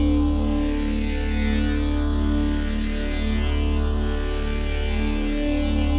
Index of /musicradar/dystopian-drone-samples/Non Tempo Loops
DD_LoopDrone4-D.wav